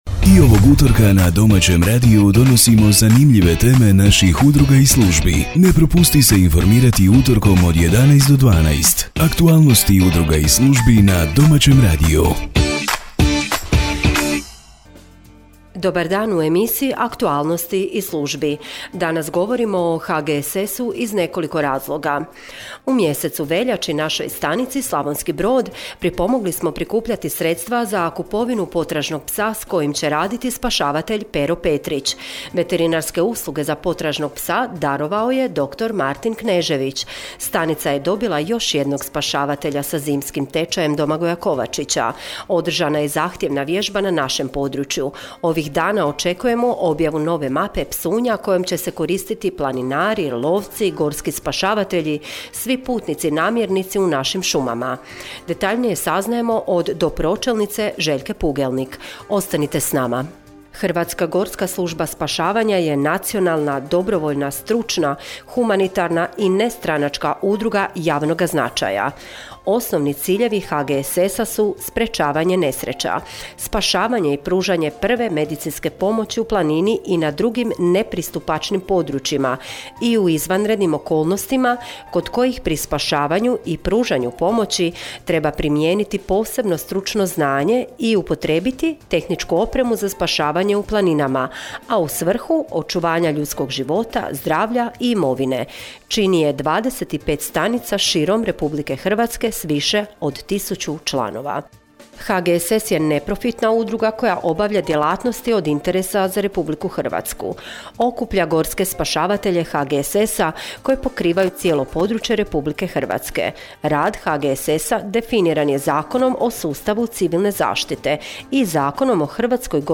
Radijska emisija: Aktualnosti iz službi